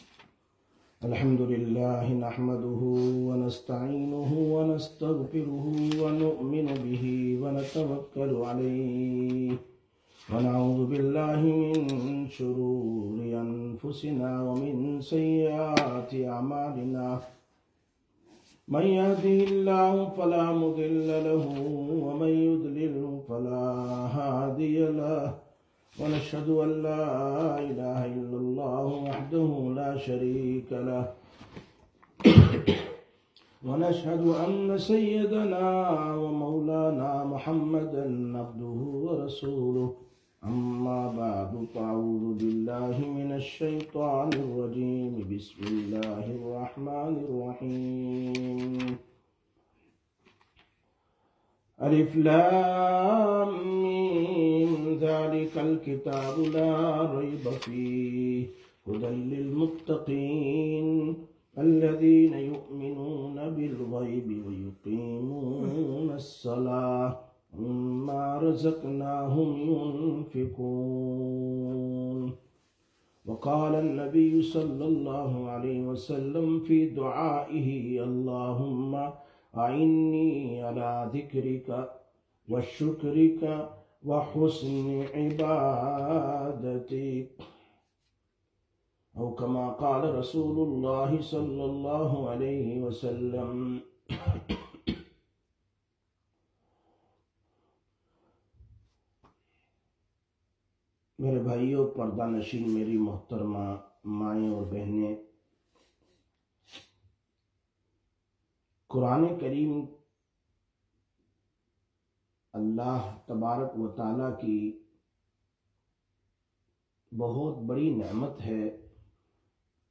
17/12/2025 Sisters Bayan, Masjid Quba